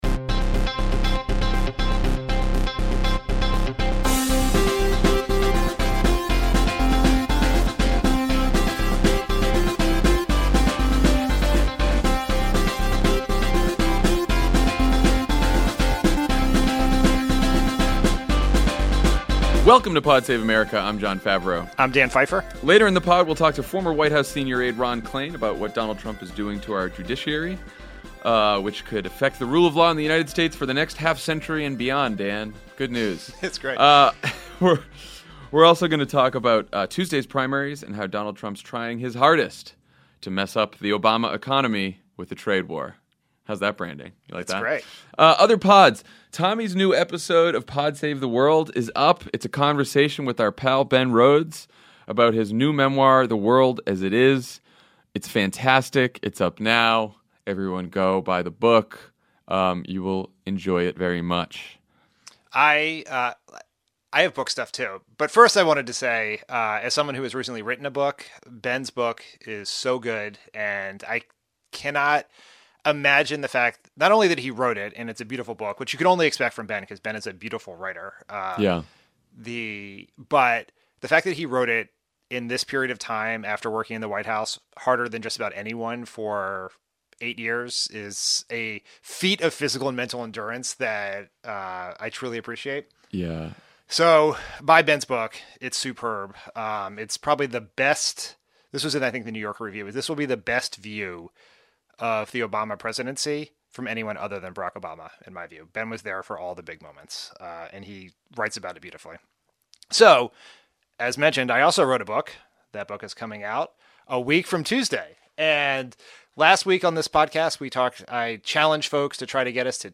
Tuesday’s primaries point to the importance of Democratic turnout in November, Trump escalates his trade war, and Paul Ryan is briefly reunited with his spine. Then former White House senior advisor Ron Klain joins Jon and Dan to talk about Mitch McConnell’s success in packing the courts with Trumpy judges.